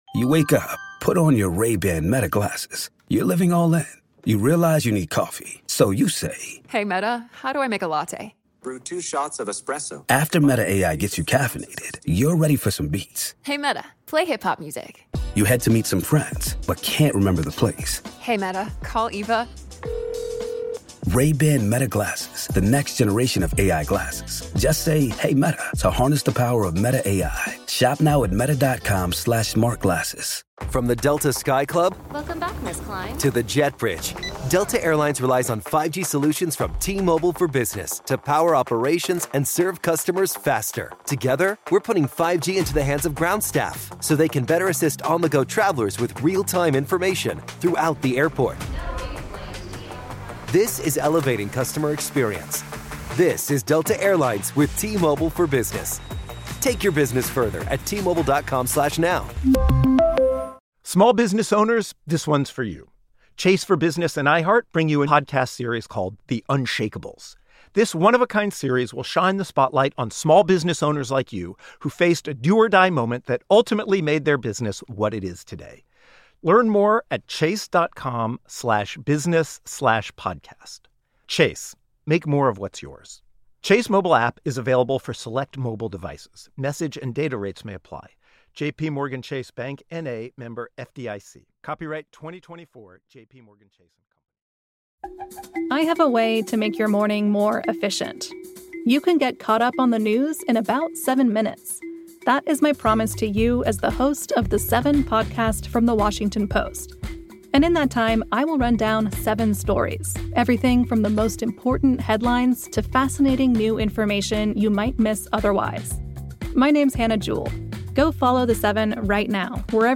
Tim Harford, author of the bestselling book 50 Inventions That Shaped the Modern Economy, shares the stories of three inventions that changed the way we live today.